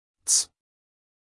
us_phonetics_sound_treats_2023feb.mp3